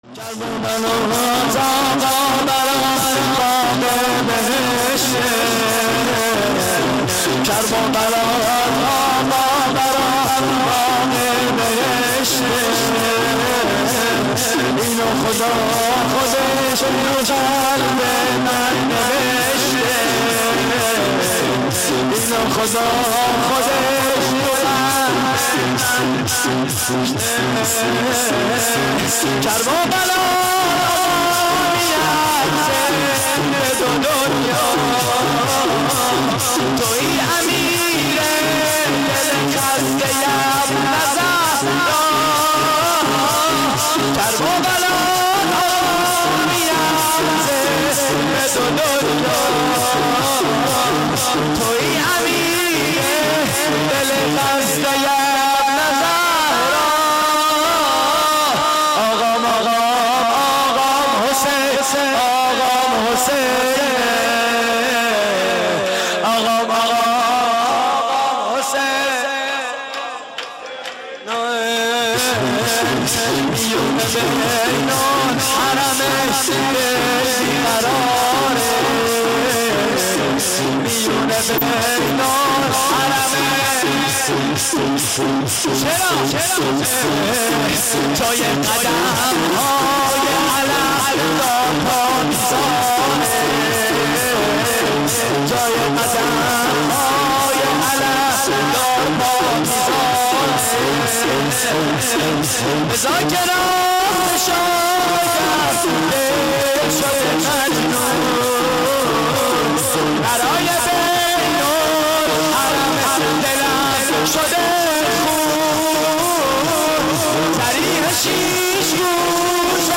شور: کرببلات آقا برام باغ بهشته
مراسم عزاداری شب هشتم ماه محرم / هیئت کریم آل طاها (ع) – شهرری